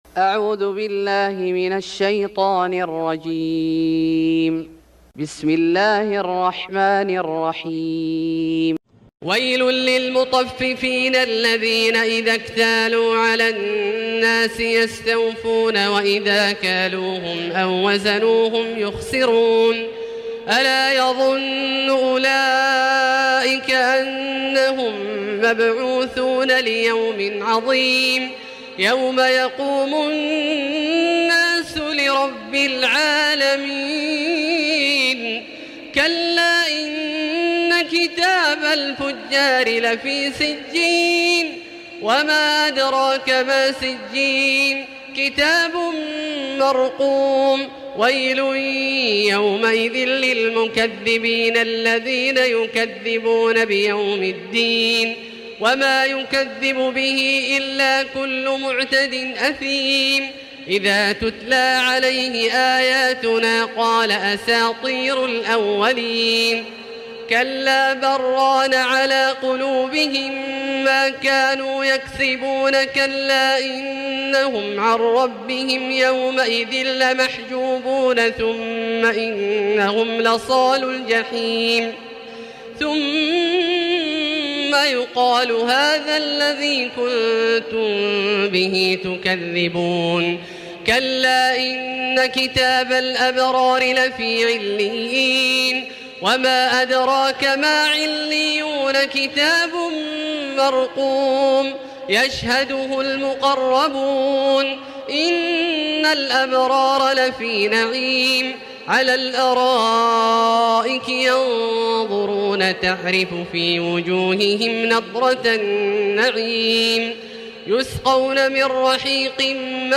سورة المطففين Surat Al-Mutaffifin > مصحف الشيخ عبدالله الجهني من الحرم المكي > المصحف - تلاوات الحرمين